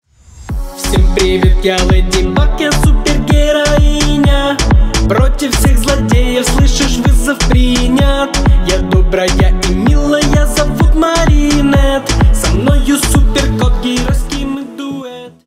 • Качество: 320 kbps, Stereo
Из мультфильмов